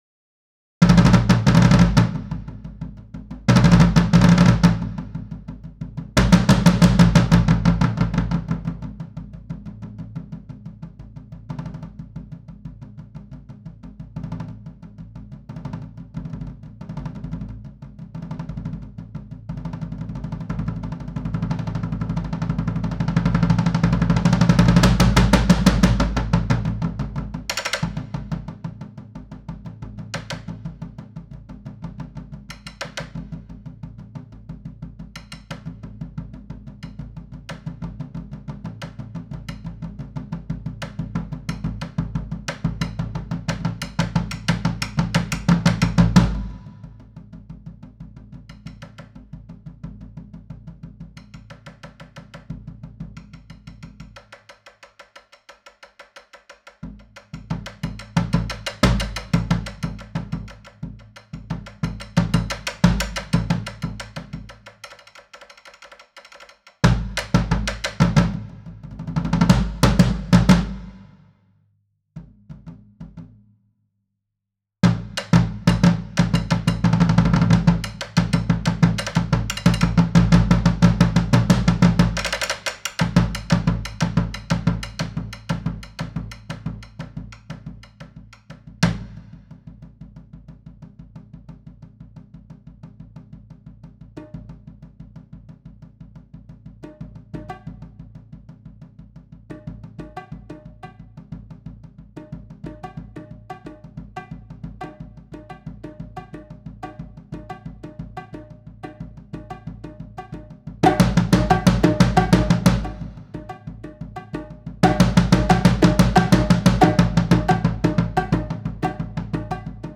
Voicing: Percussion Duet